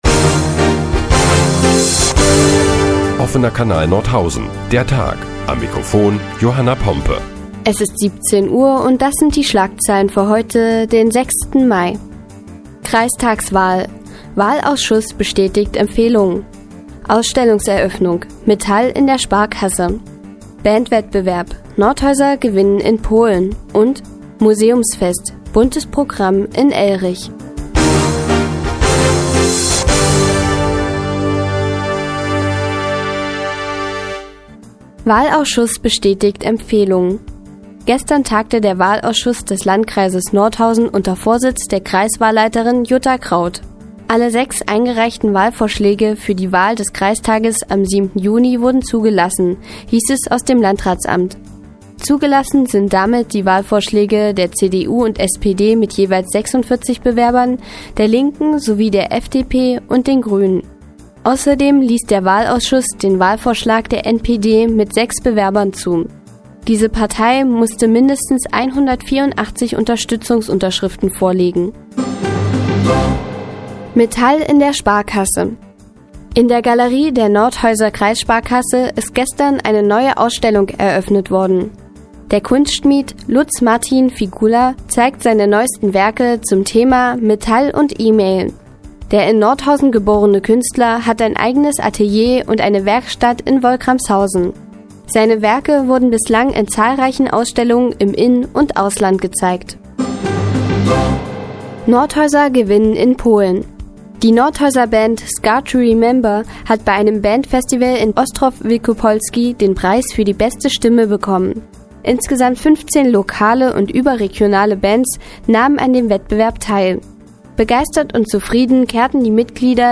Die tägliche Nachrichtensendung des OKN ist nun auch in der nnz zu hören. Heute geht es unter anderem um die bevorstehende Kreistagswahl und um erfolgreiche Nordhäuser in einem polnischen Bandwettbewerb.